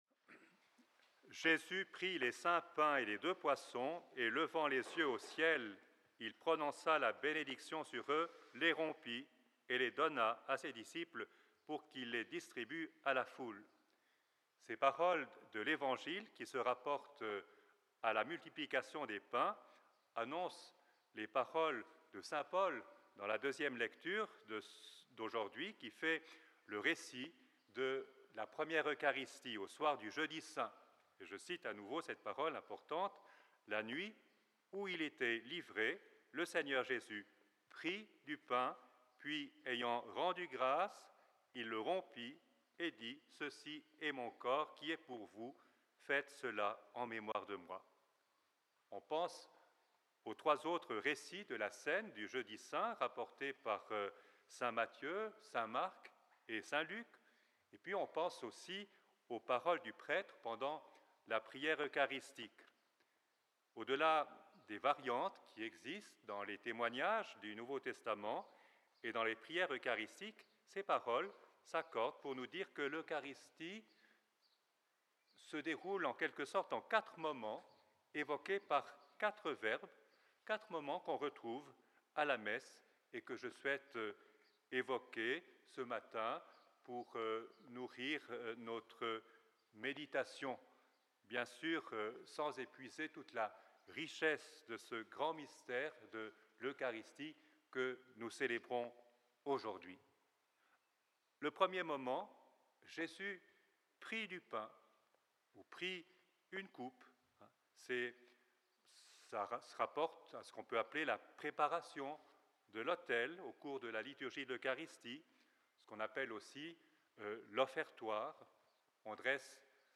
Alors que la Solennité du Saint-Sacrement (la Fête-Dieu) sera célébrée dimanche dans certains endroits (comme à Genève), elle a été célébrée le jour traditionnel, aujourd'hui, à Fribourg.